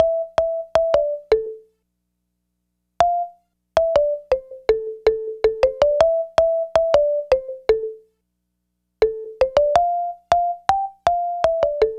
Wood Xylo.wav